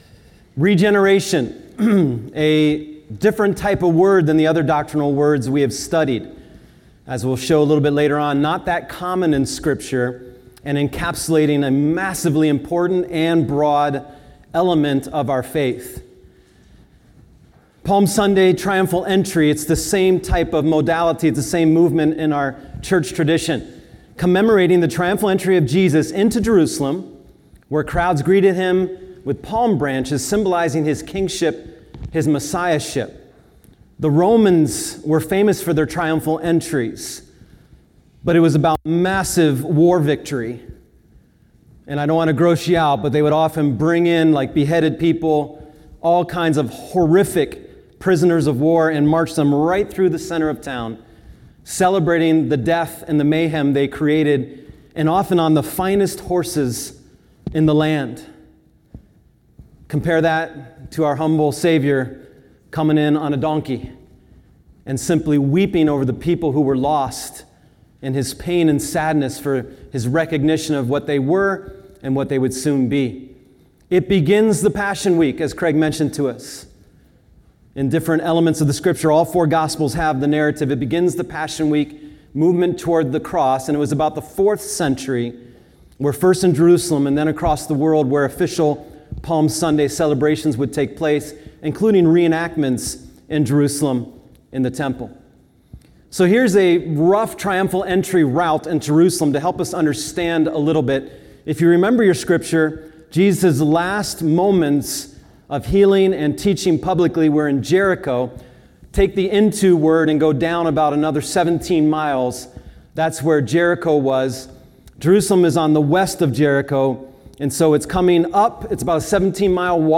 Sermons | Solid Rock Church